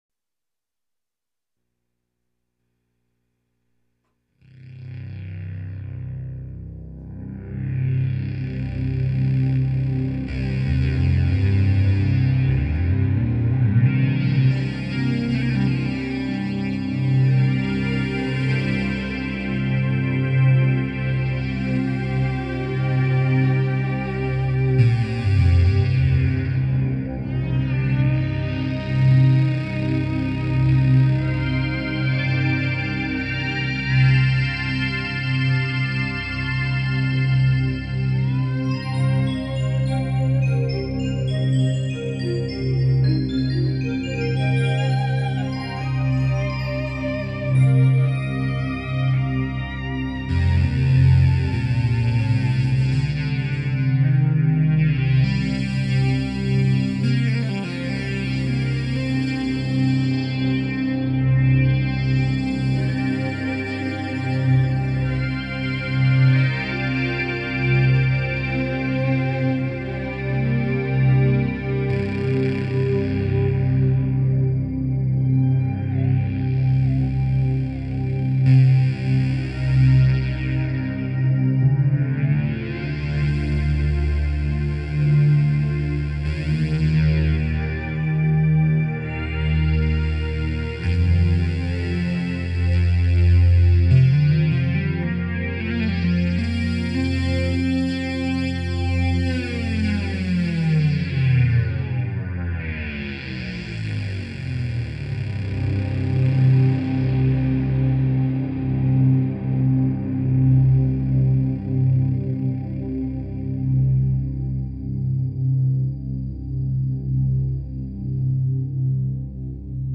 Santa Cruz home studio recordings  (1999)
3 guitars, bass and drums
keyboards, guitar